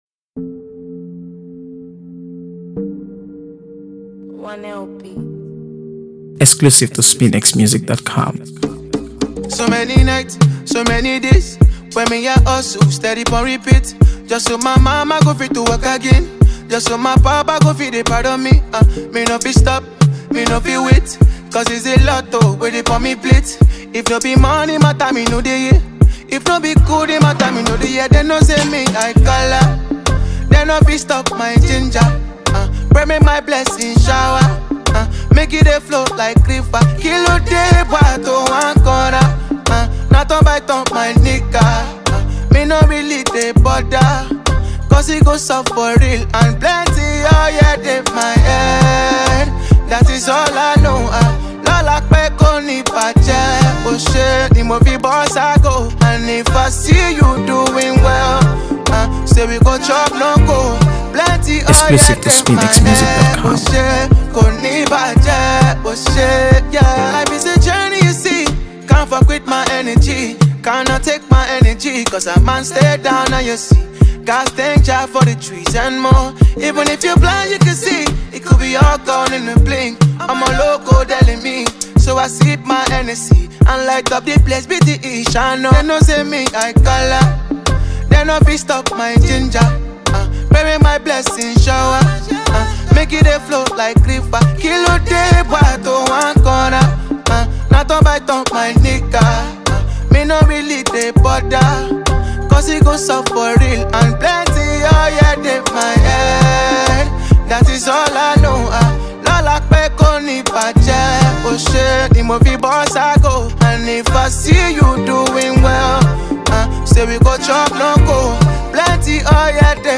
AfroBeats | AfroBeats songs
Built on rich Afrobeats rhythms
smooth, expressive vocals